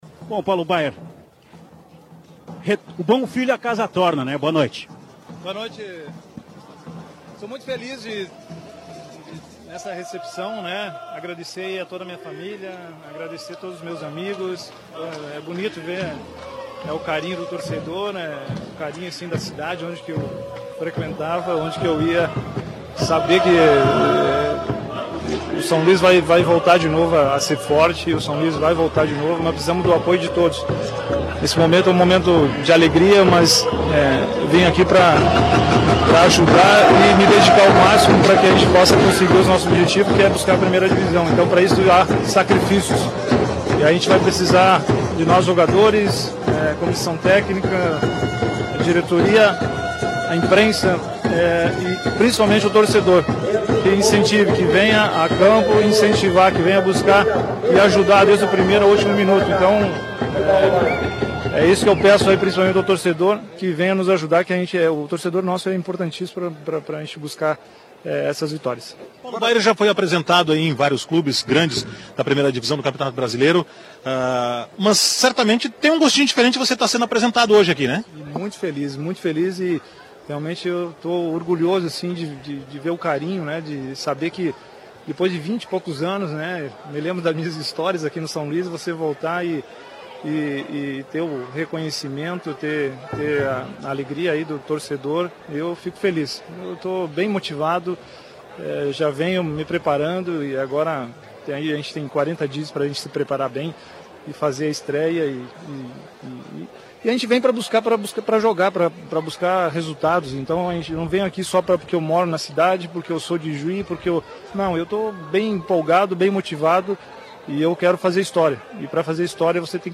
Coletiva do Paulo Baier ao ser apresentado no São Luiz